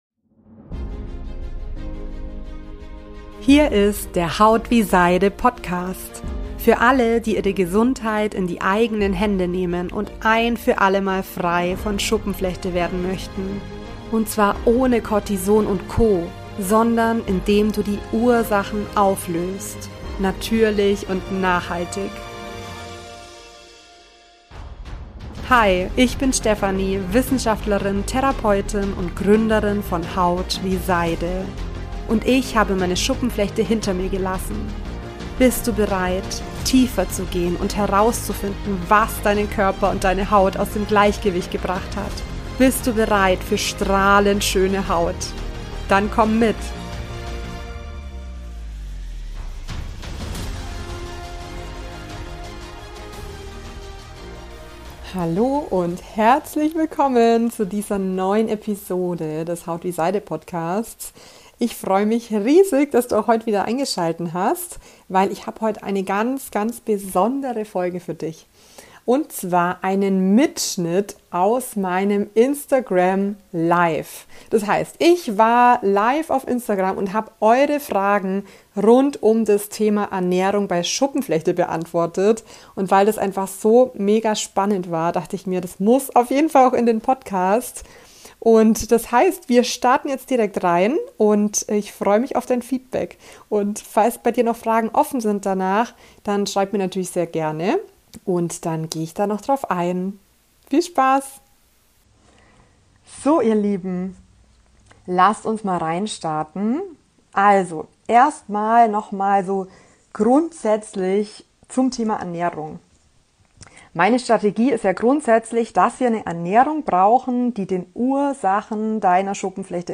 Und zwar hab ich in dieser Episode einen Mitschnitt von meinem Live-Q&A für dich. Bei dem so grandiose Fragen rund um das Thema Ernährung bei Schuppenflechte kamen, die ich dir auf keinen Fall vorenthalten möchte.